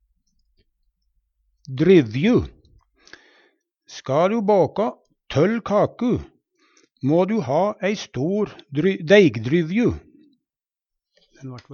dryvju - Numedalsmål (en-US)